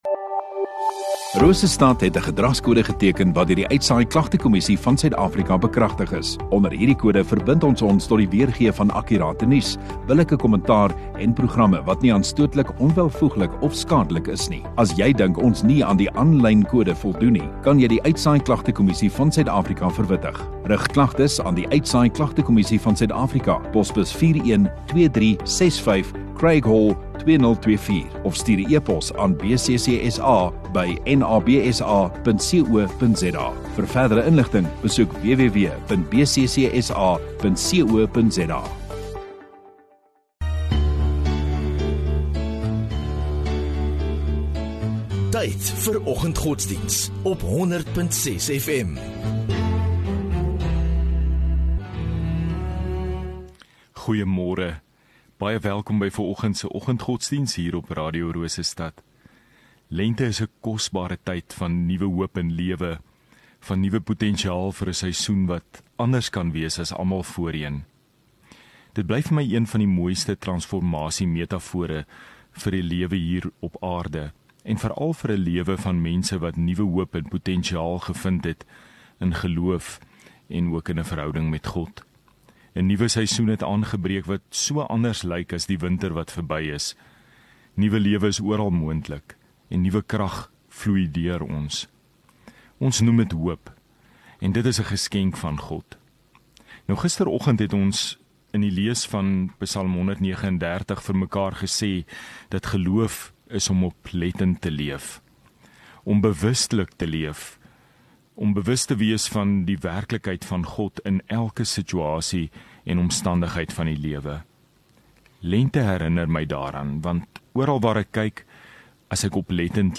5 Sep Vrydag Oggenddiens